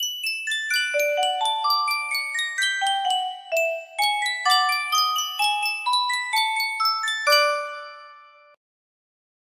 Sankyo Miniature Music Box - Sweet Adeline 7T music box melody
Sankyo Miniature Music Box - Sweet Adeline 7T
Full range 60